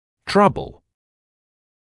[‘trʌbl][‘трабл]проблемы; неприятности; сложности